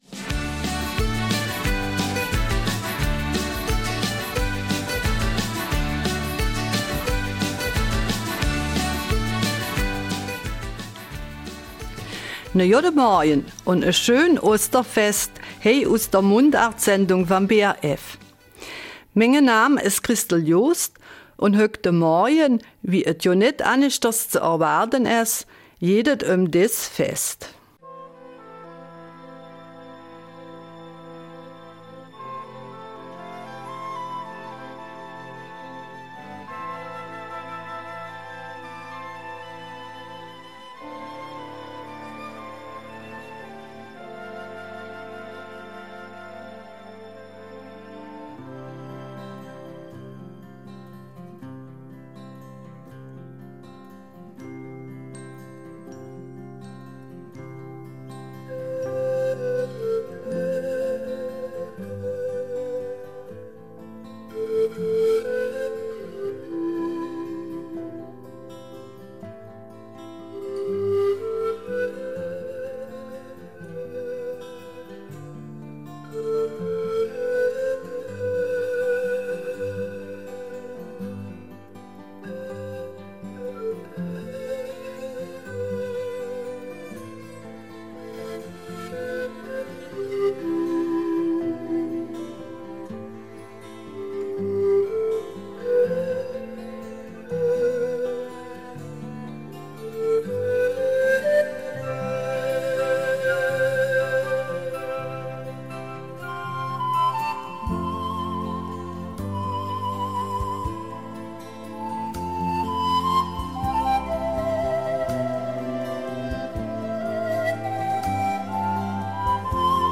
Eifeler Mundart: Von Ostereiern und Osterhasen